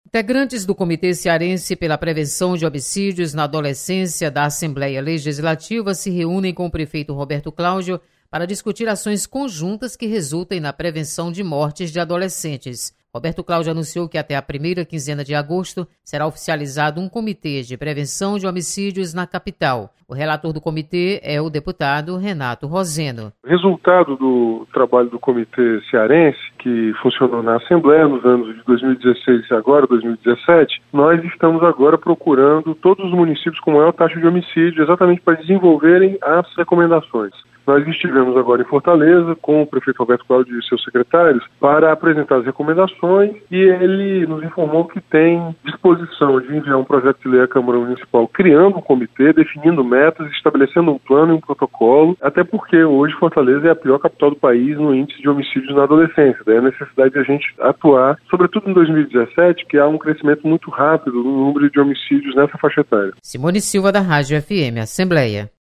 Deputado Renato Roseno comenta sobre criação de comitê de prevenção a homicídios em Fortaleza. Repórter